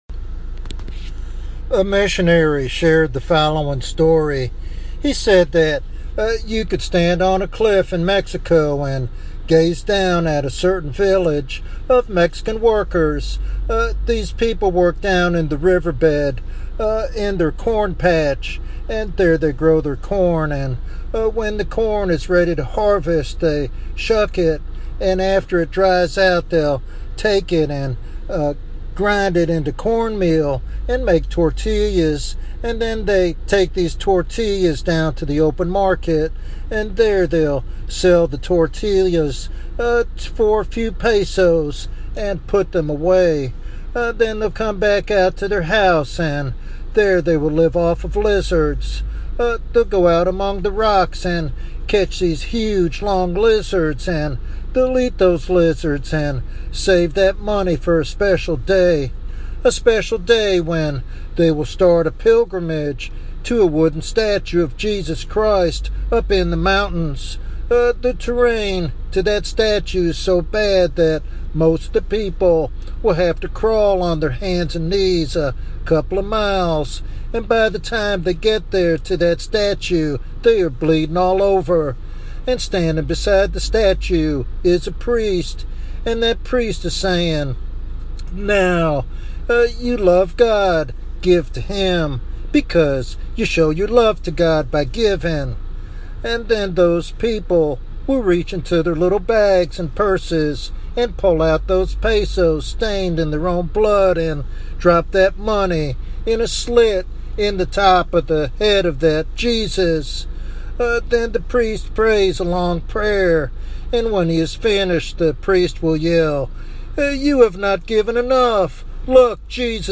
He emphasizes the necessity of Holy Spirit conviction and the new birth as the only way to salvation. This sermon is a sobering reminder of the eternal consequences of rejecting the true gospel.